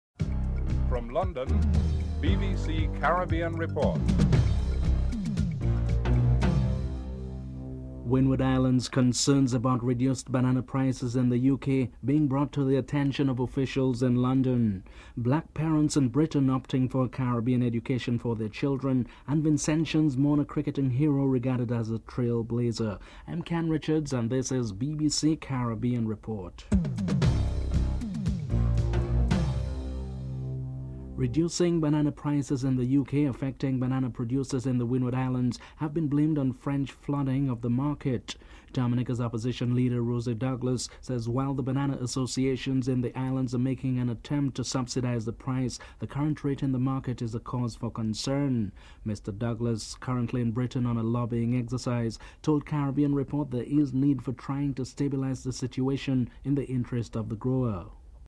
1. Headlines (00:00-00:30)
Windward Islands concerns about reduced banana prices in the United Kingdom are being brought to the attention of officials in London. Dominca's Opposition Leader Rosie Douglas is interviewed (00:31-03:41)